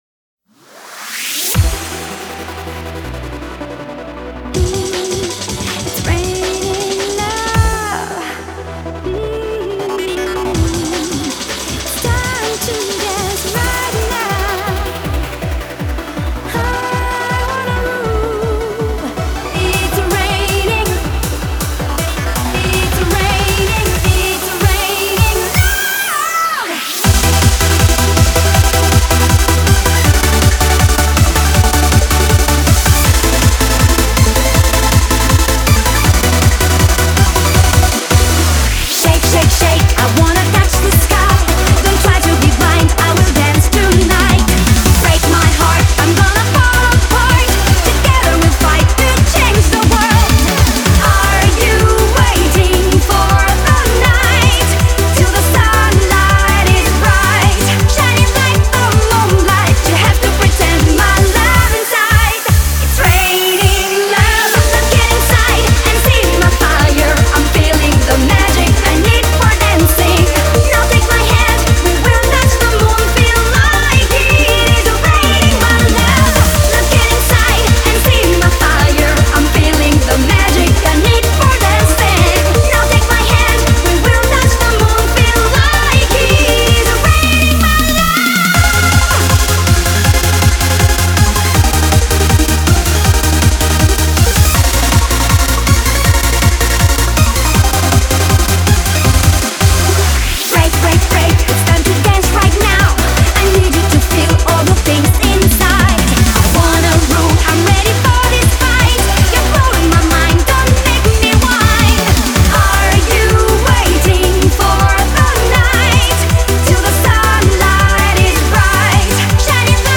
• Категория:Евробит